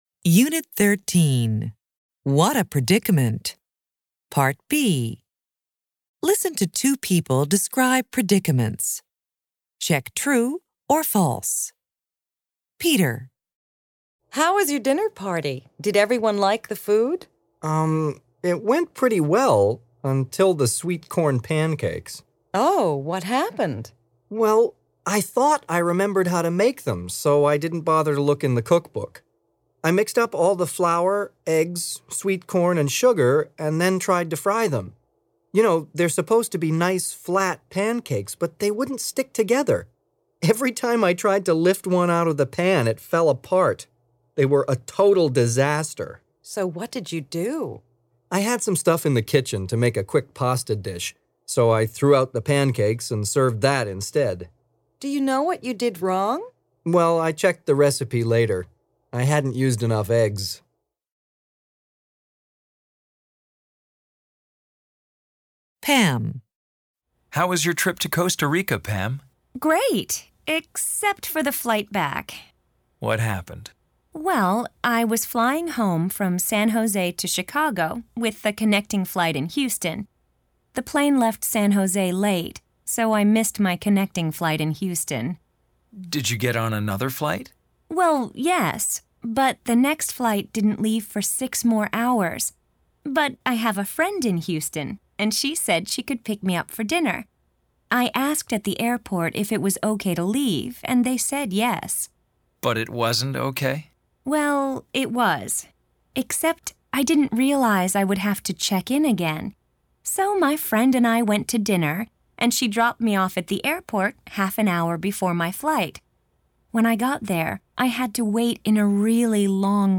American English